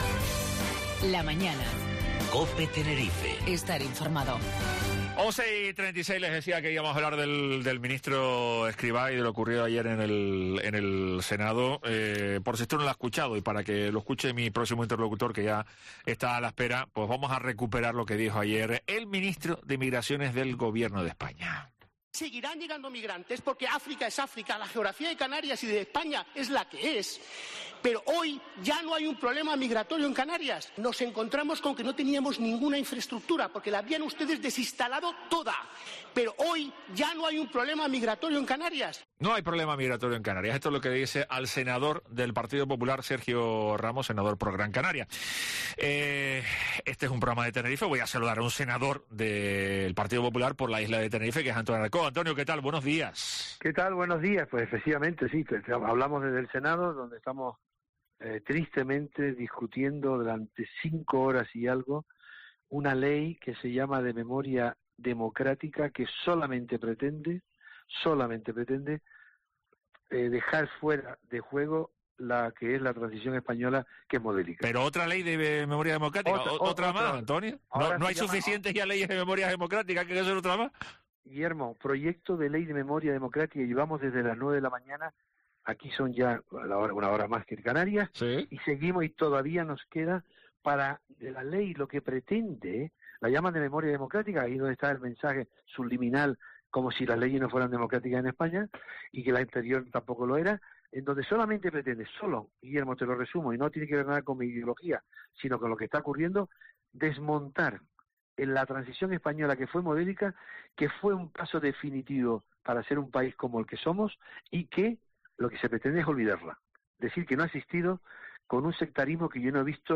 Entrevista Antonio Alarcó